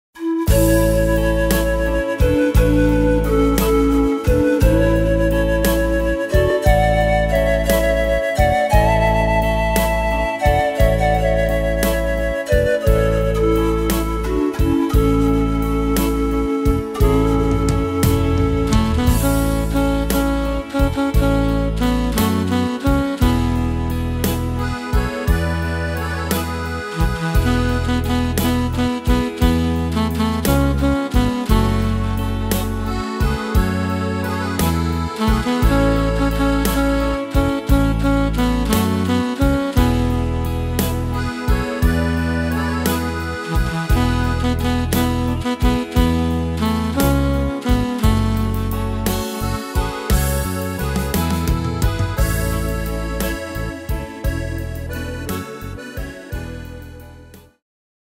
Tempo: 58 / Tonart: A-Dur